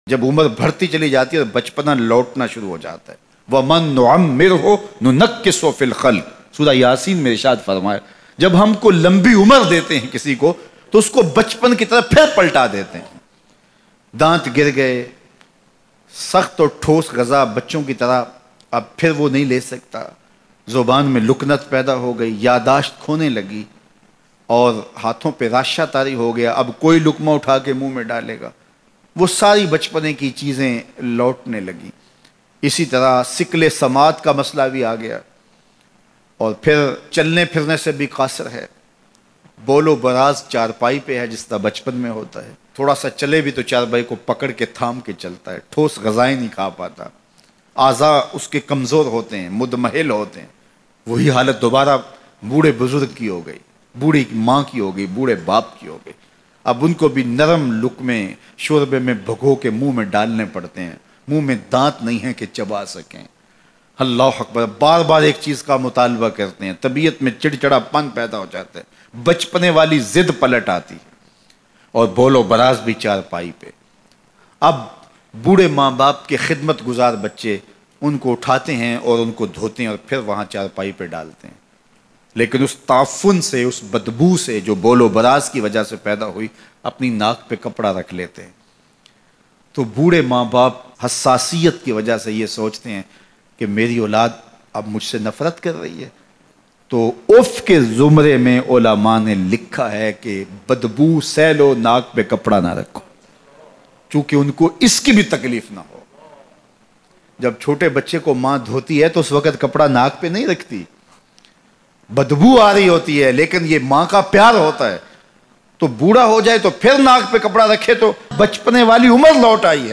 Badboo seh lo mgr nak pr kapra na rakho Bayan